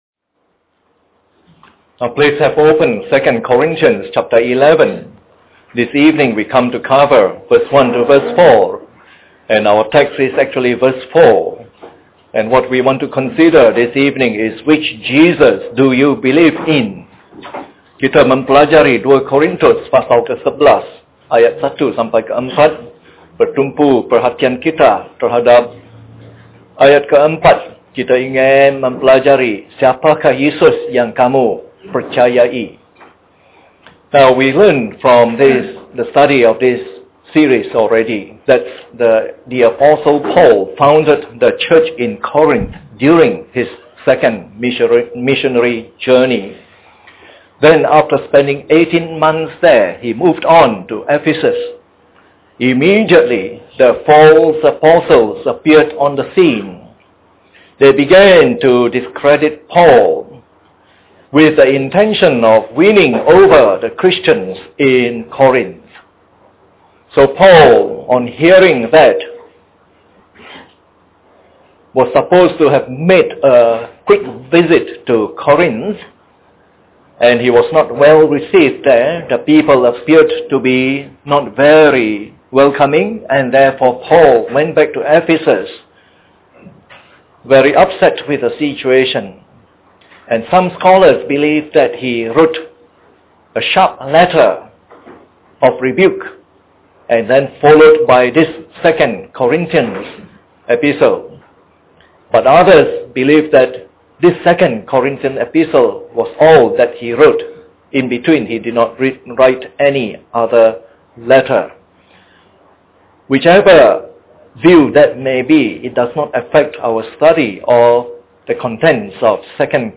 This is part of the “Selected 2 Corinthians” evangelistic series delivered in the Evening Service.